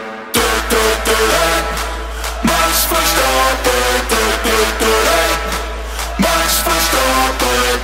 maks ferstappen Meme Sound Effect